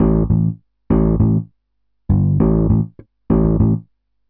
You can now be a one man band, keys marked with a "G" are electric guitar riffs, keys marked with a "B" are bass riffs, keys marked with a "D" are drum solos, mix and match and ROCK OUT!